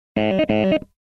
На этой странице представлена подборка звуков BIOS, включая редкие сигналы ошибок и системные оповещения.
Ошибка жесткого диска